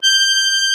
MUSETTE 1.21.wav